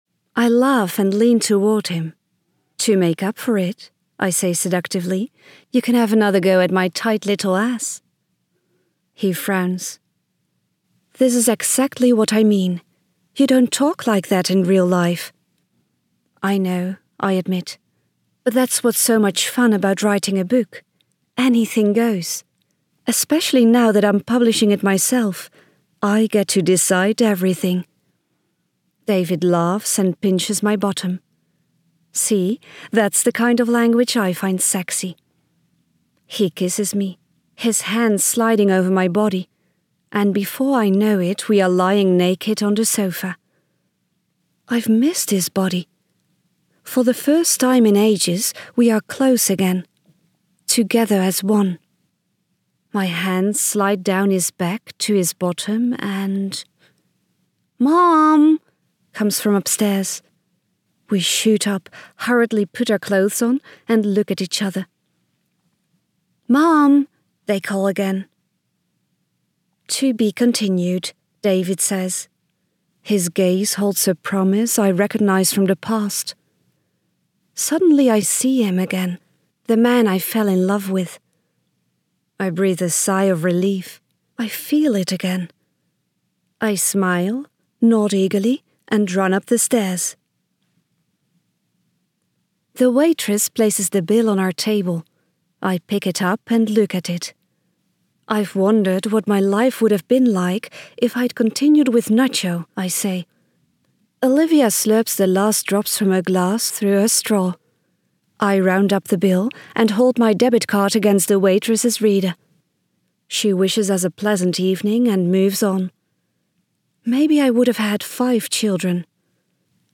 end_audiobook - Het is goed zo